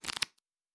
Cards Shuffle 1_09.wav